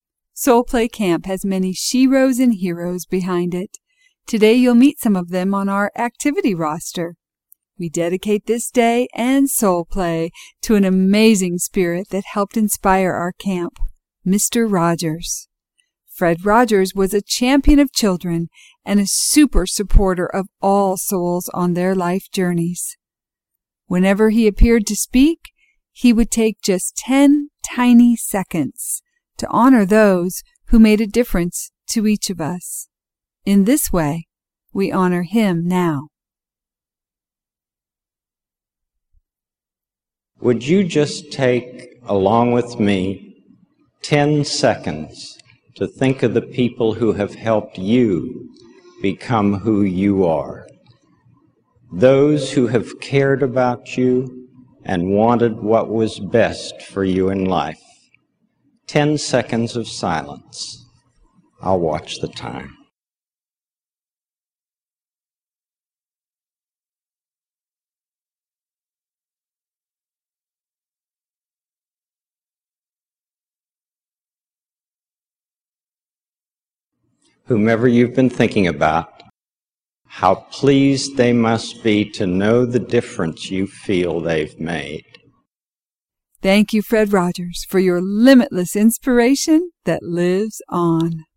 Audio Tribute (1:53) & Silence for Mr. Rogers